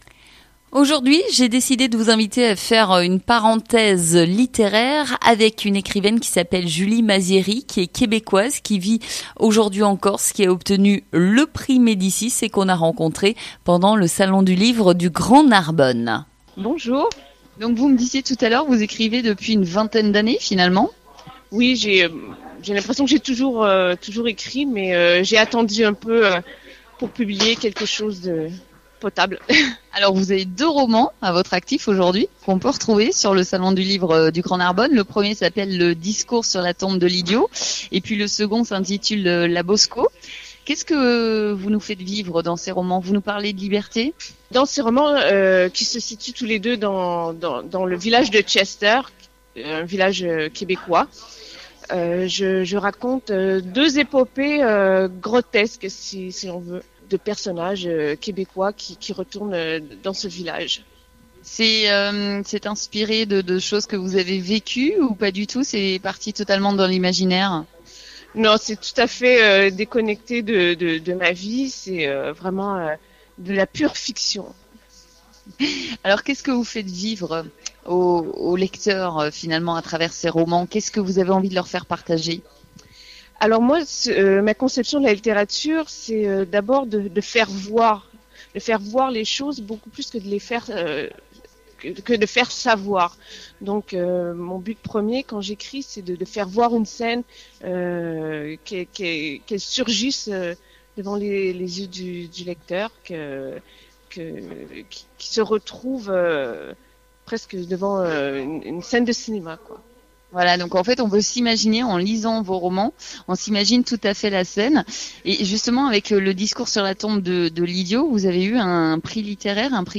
GRAND SUD FM en direct du cours Mirabeau à Narbonne